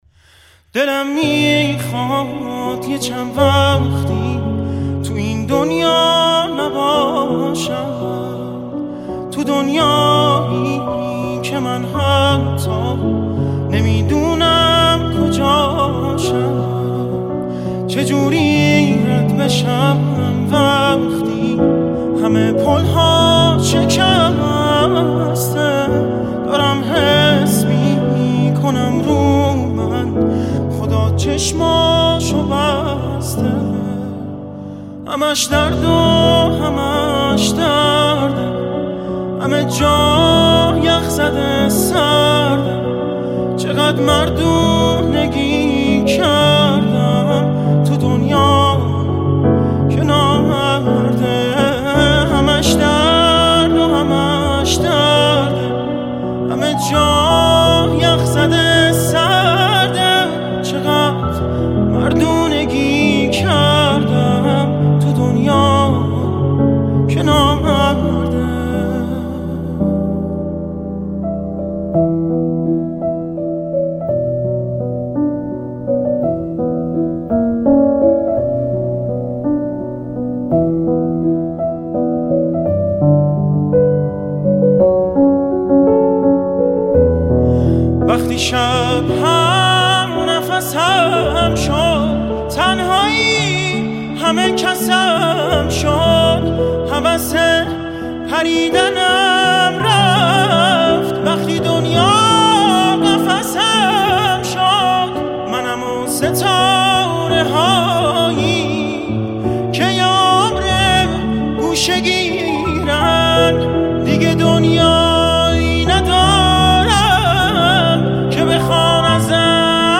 ورژن پیانو